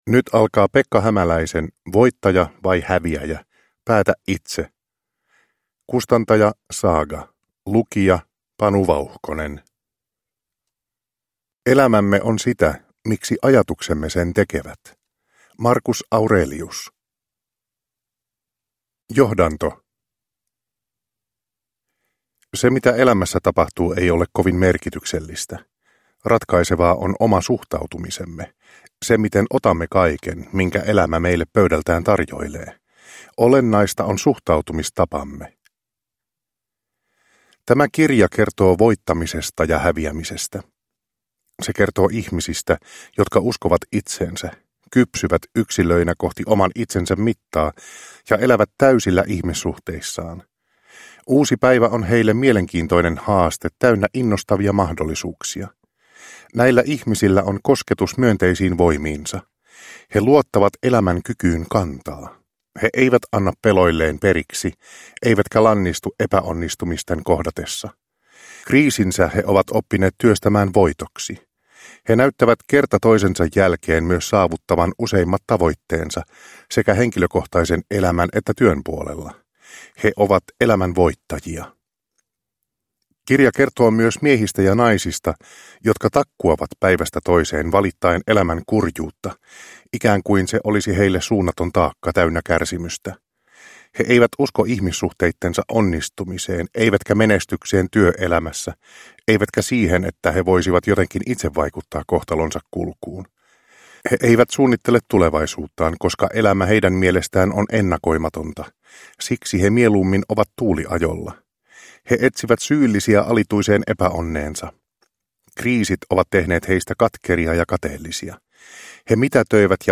Voittaja vai häviäjä - päätä itse! (ljudbok)